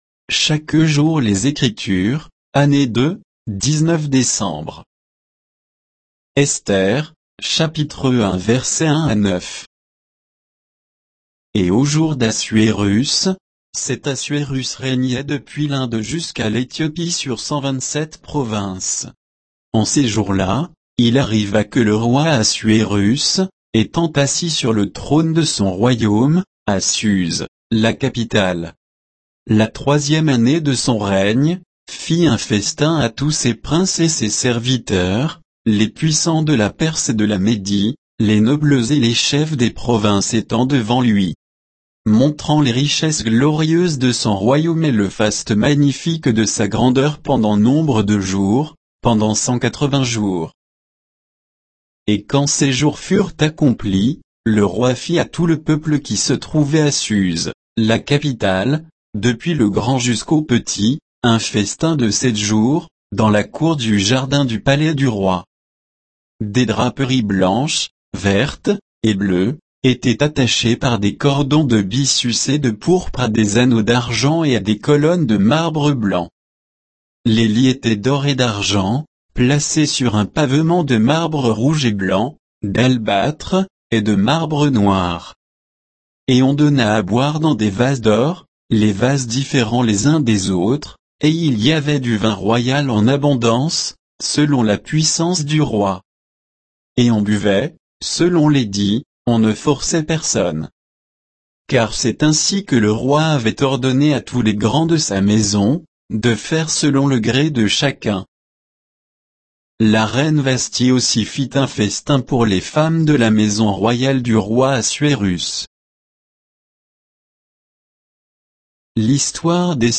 Méditation quoditienne de Chaque jour les Écritures sur Esther 1